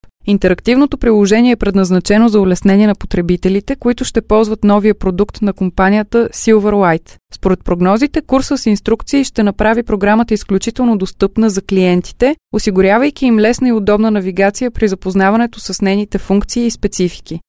Sprecherin bulgarisch für Werbung, TV, Hörfunk, Industrie, Podcast
Sprechprobe: Werbung (Muttersprache):
Professional female bulgarian voice over artist